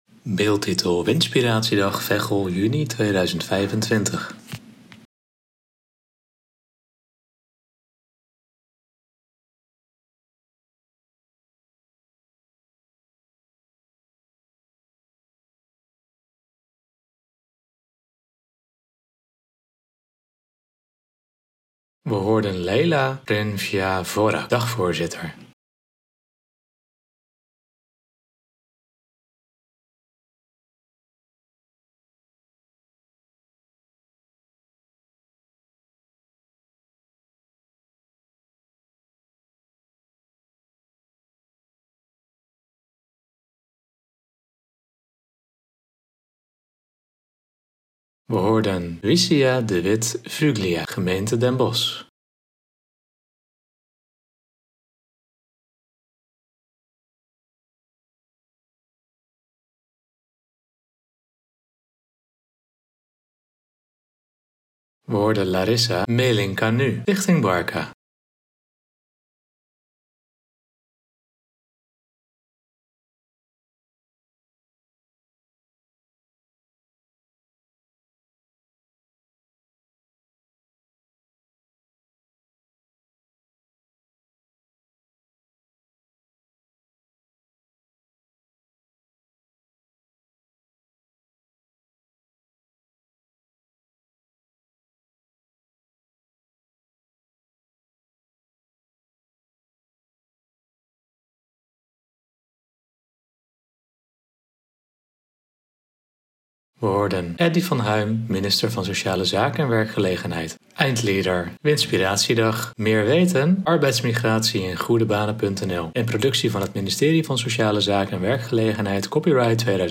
Bekijk hieronder de aftermovie van de WINspiratiedag.